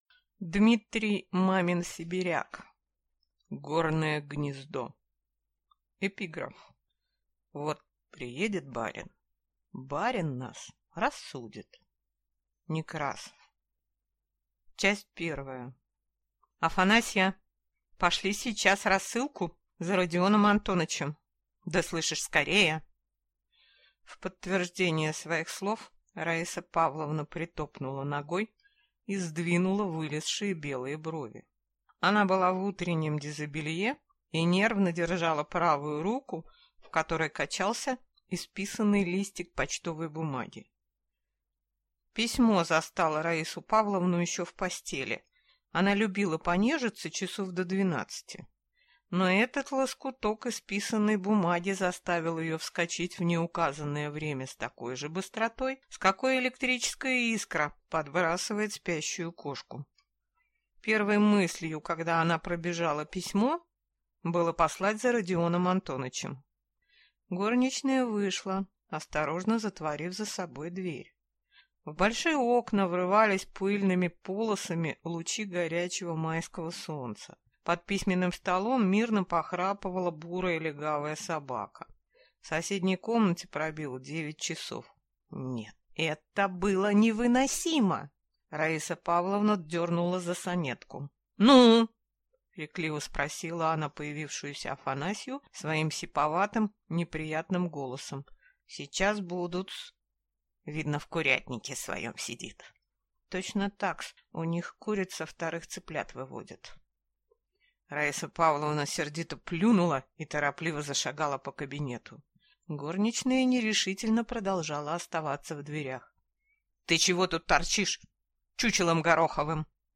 Аудиокнига Горное гнездо | Библиотека аудиокниг